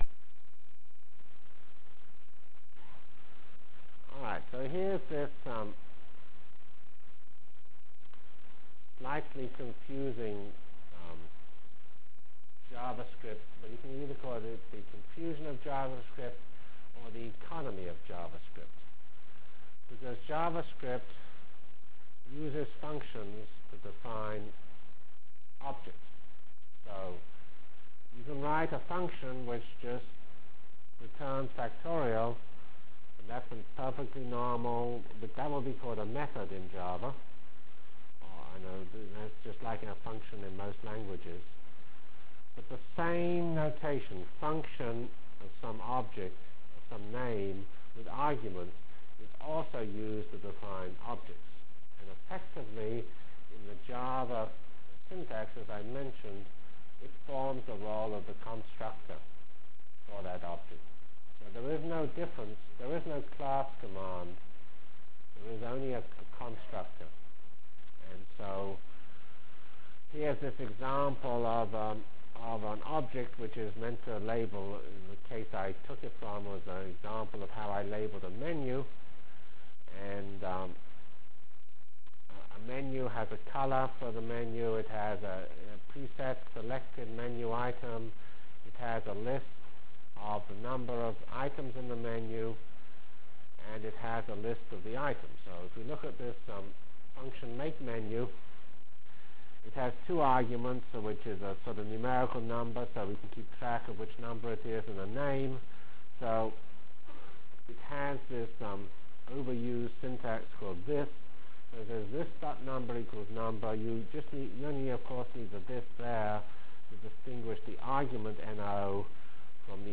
Delivered Lecture